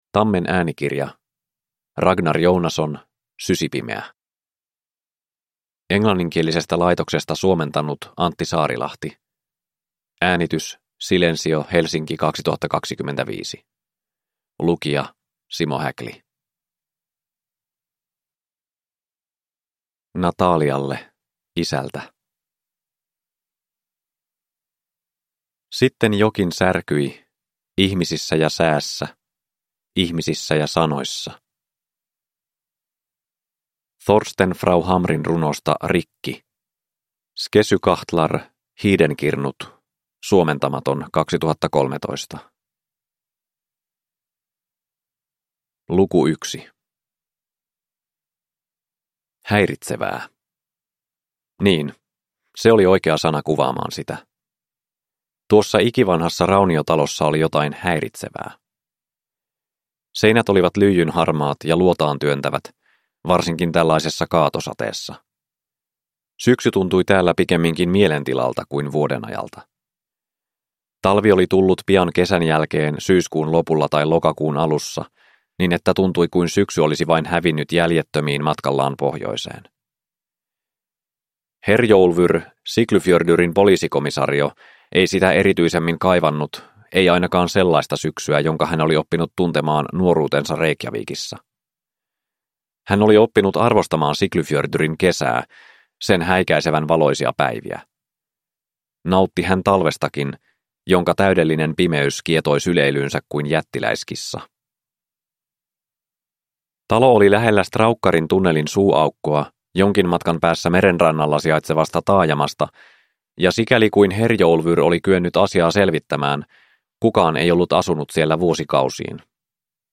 Sysipimeä (ljudbok) av Ragnar Jónasson